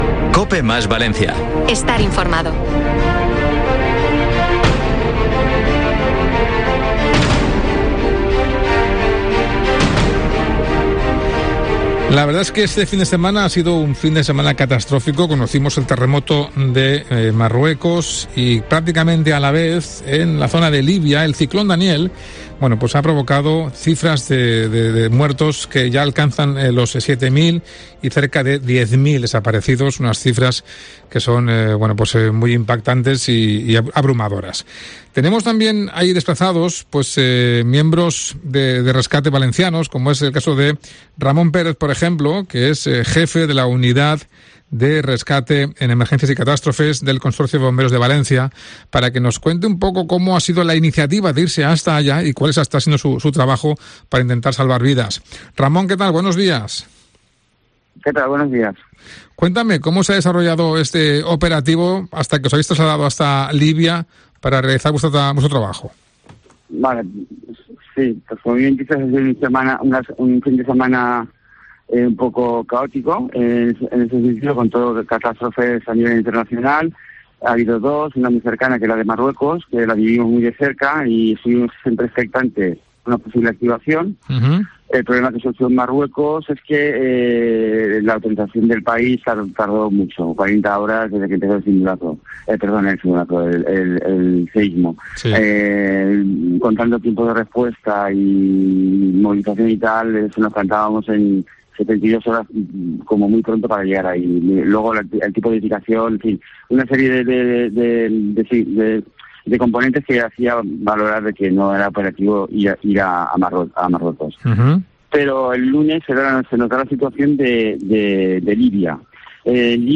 ENTREVISTA | Un bombero valenciano en Libia cuenta las claves de la virulencia del suceso